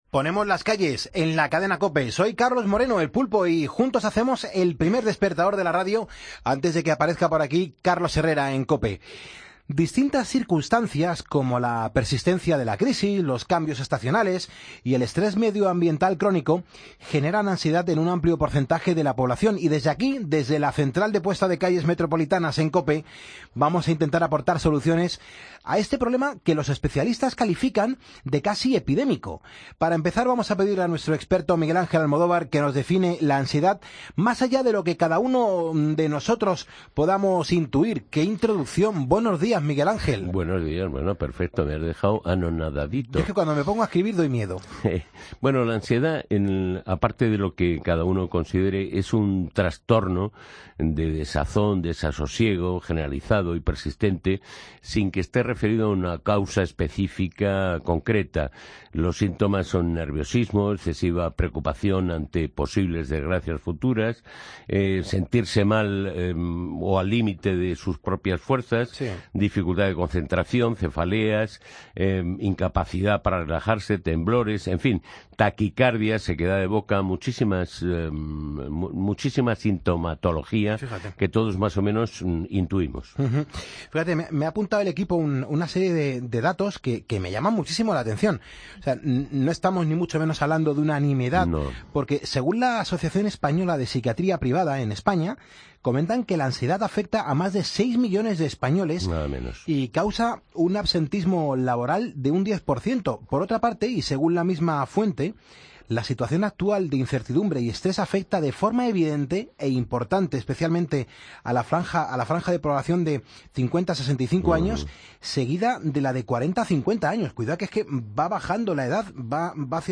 Con el experto nutricionista